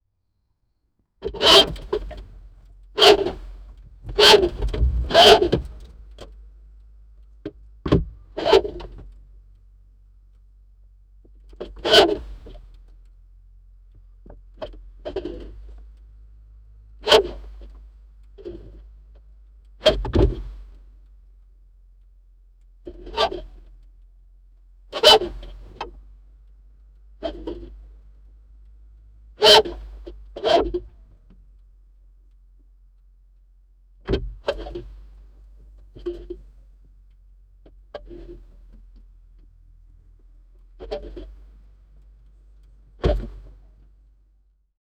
Grey Wolf Standing On Metal Hood Of Matte Black 1969 Dodge Charger R/T, Wolf Paw Steps On The Hood, Grey Wolf Panting. Grey Wolf Barking, Grey Wolf Howling
grey-wolf-standing-on-met-6nq5jun2.wav